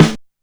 Snare (13).wav